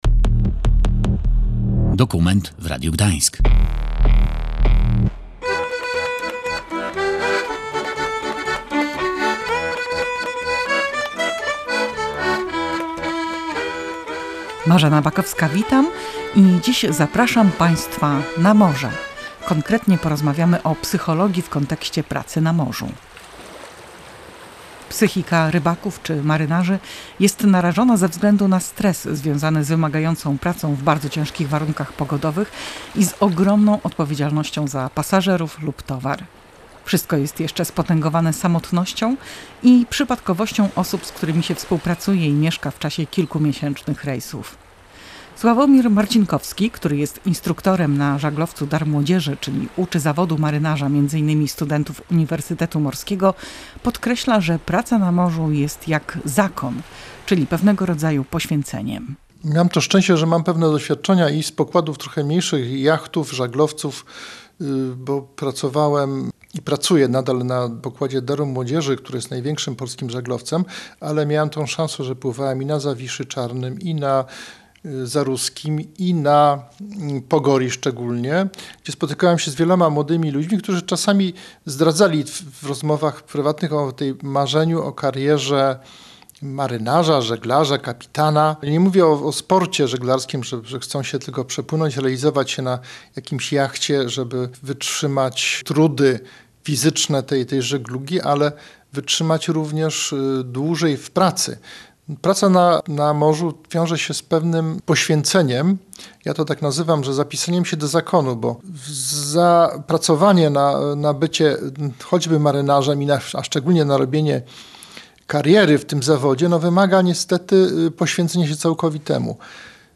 W audycji o obecnych i dawnych aspektach pracy na morzu opowiadali marynarze